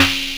Destroy - ShatterPerc.wav